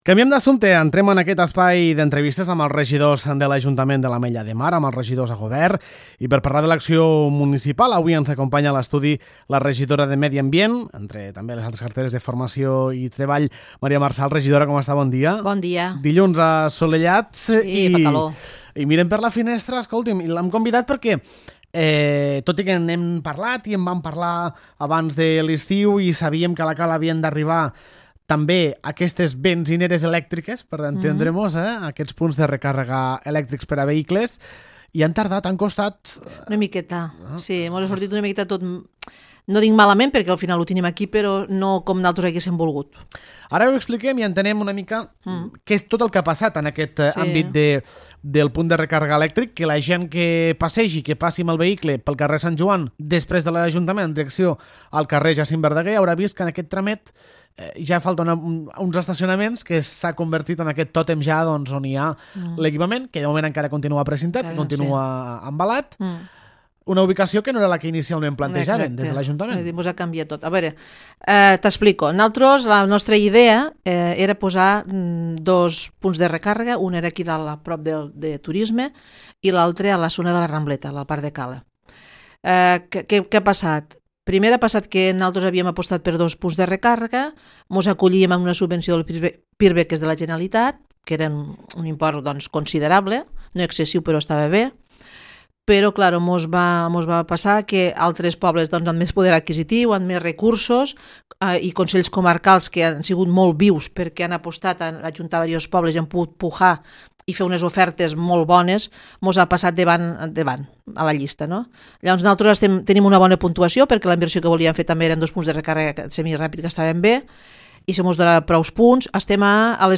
Ben aviat, La Cala disposarà en actiu del primer punt de recàrrega de vehicles elèctrics i híbrids. El tòtem s’ha instal·lat, finalment, al carrer Sant Joan just a sobre el pàrquing. La regidora de Medi Ambient, Maria Marsal, ens explica els motius i també parla sobre altres temes d’actualitat de les seves competències.
Maria Marsal, regidora de Medi Ambient